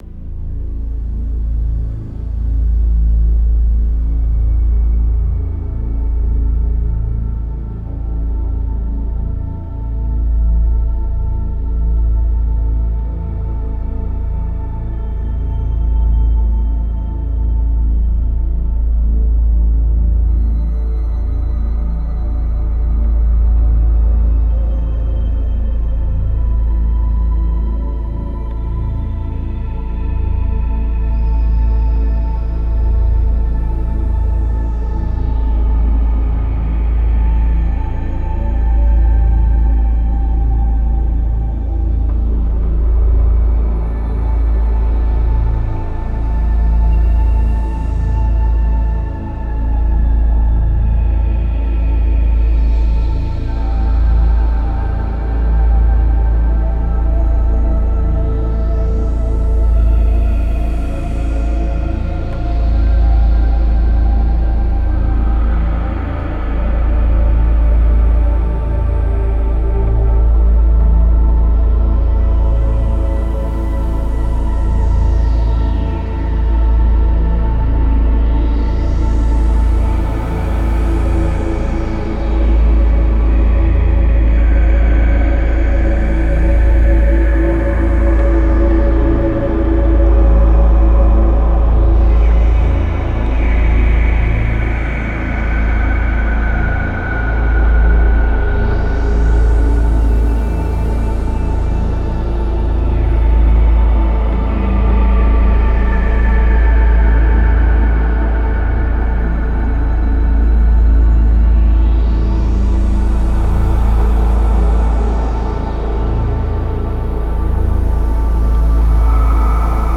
:)))) - � �������� ������� ��� ���������� ����������� Yamha Motif XF7 � Yamaha SY99, ������ (������� �����) �������� ����� ����, �� Zoom H6 ���������� ��� ��� ����������.